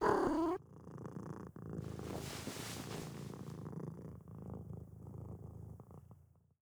sfx_猫猫钻盒子.wav